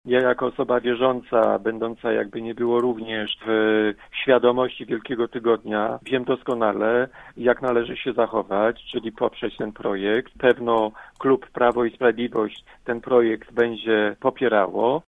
Mówi poseł PiS-u Jacek Kurzępa: